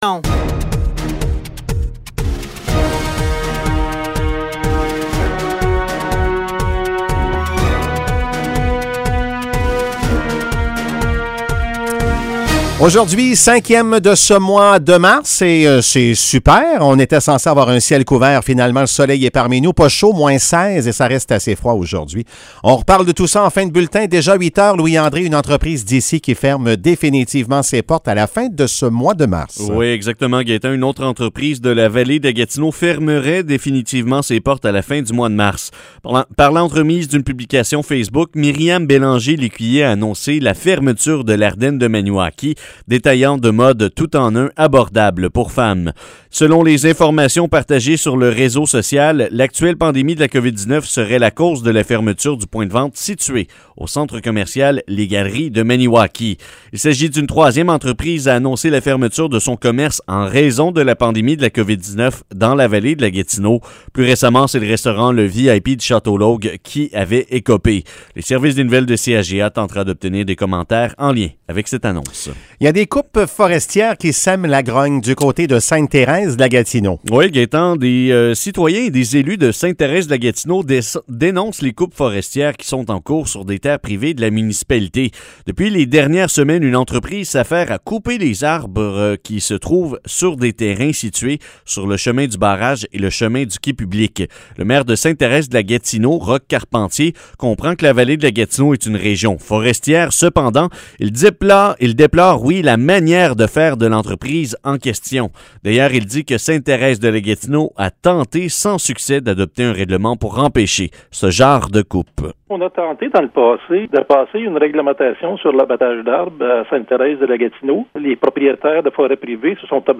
Nouvelles locales - 5 mars 2021 - 8 h